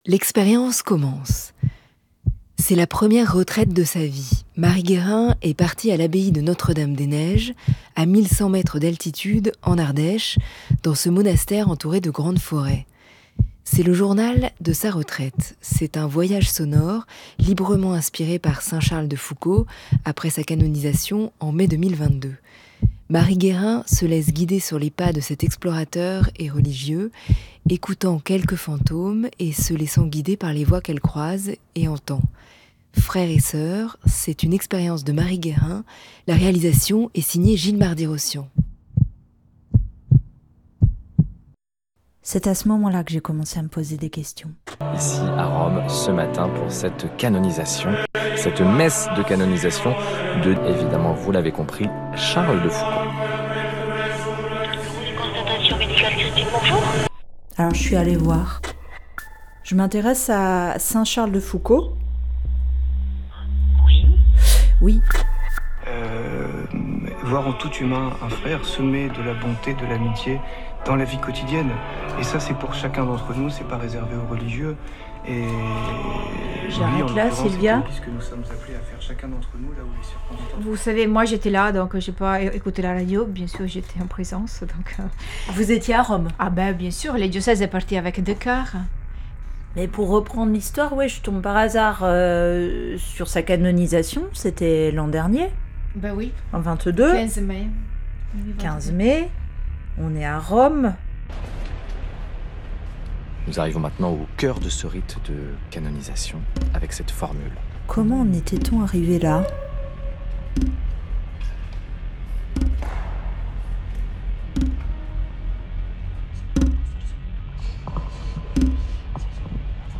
Des chants grégoriens aux chants des touaregs, une Expérience sur les traces de Saint-Charles de Foucauld, explorateur et religieux.